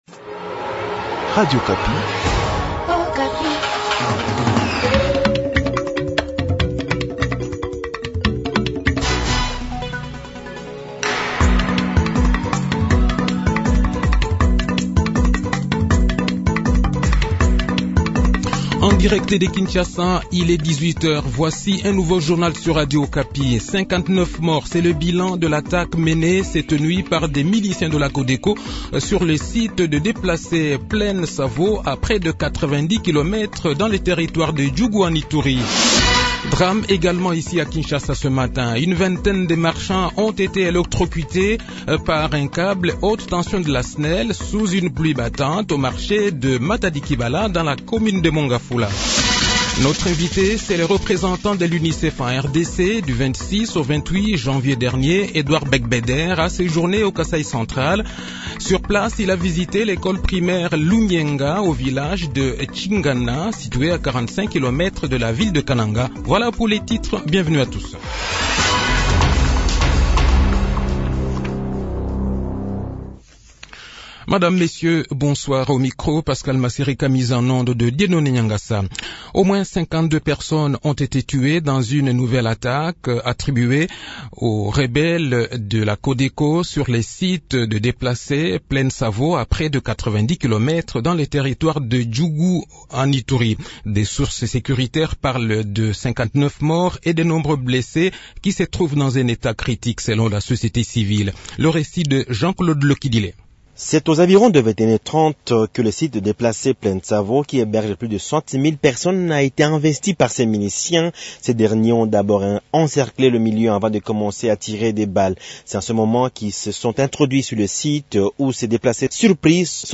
Le journal de 18 h, 2 fevrier 2022